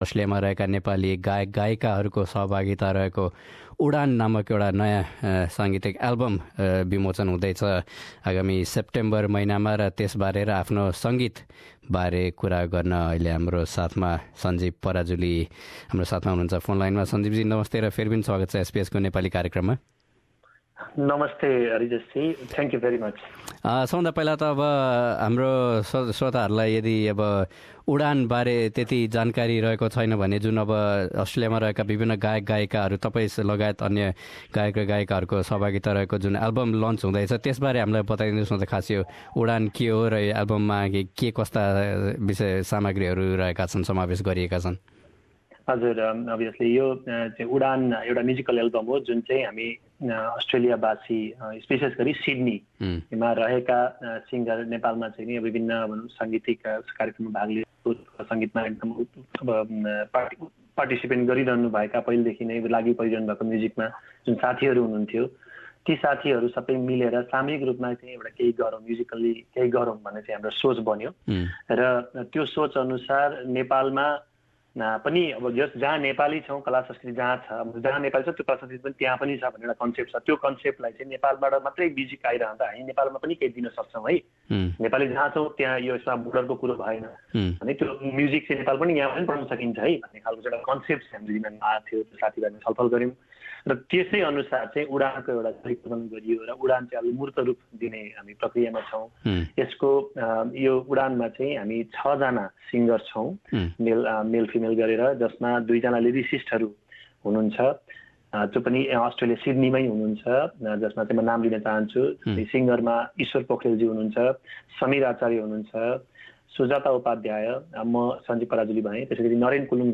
एसबीएस नेपालीसँग गरेको कुराकानी